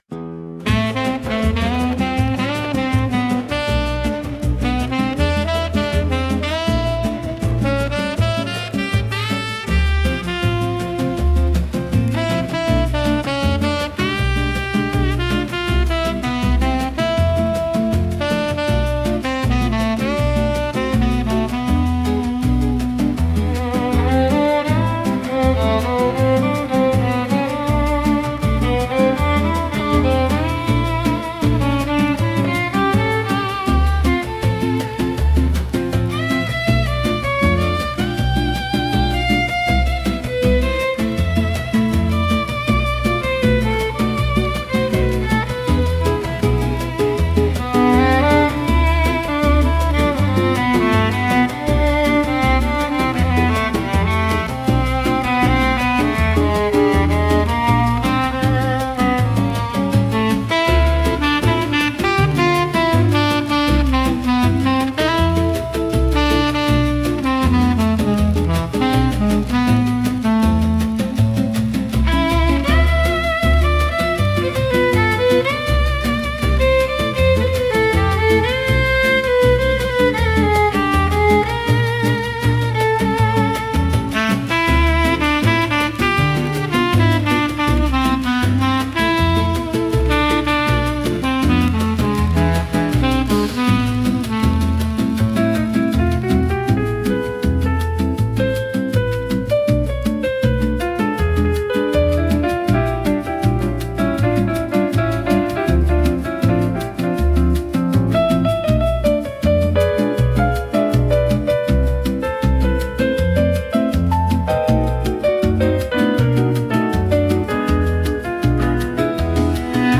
música e arranjo IA) instrumental 7